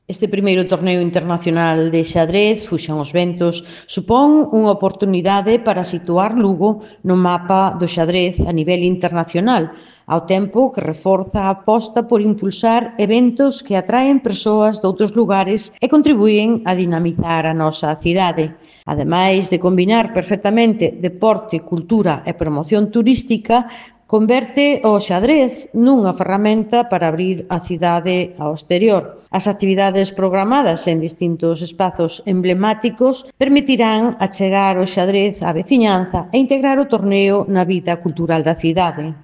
• A concelleira de Cultura, Turismo e Promoción da Lingua, Maite Ferreiro, sobre o I Torneo Internacional de Xadrez Fuxan os Ventos |